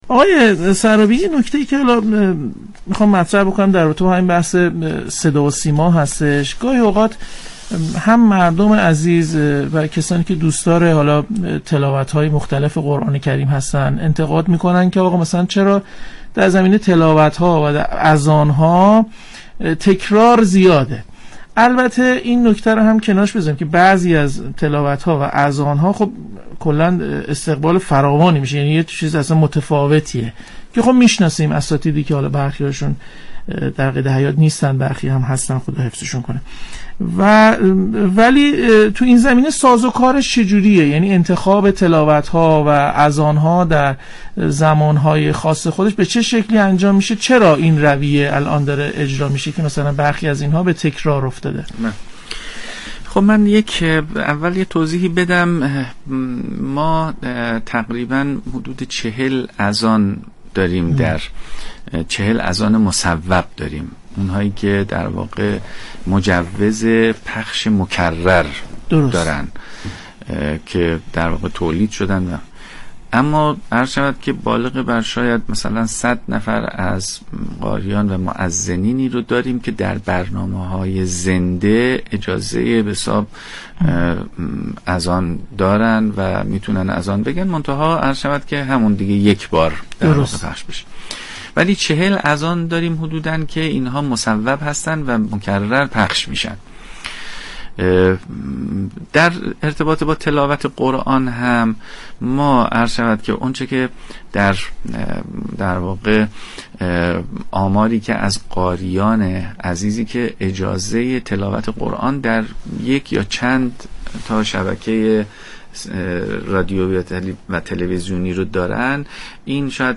سید علی سرابی قائم مقام شورای عالی قرآن در گفتگو با برنامه والعصر 22 آبان 1400 در باره تكرار اذان ها و تلاوت های مجاز گفت : تقریباً حدود 40 مورد از اذان‌های پخش شده در صدا و سیما اذان مصوب در شورای عالی قرآن است كه مجوز پخش مكرر دارند.